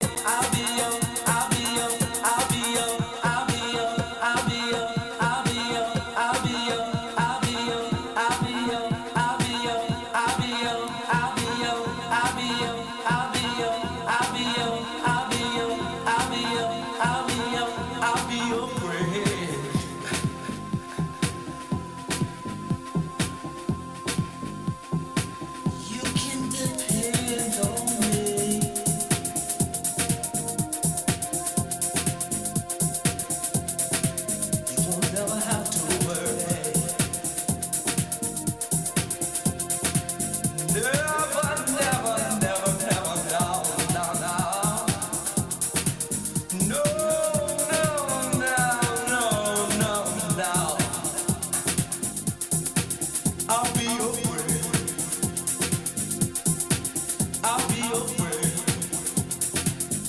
Literally one of the deepest tracks out there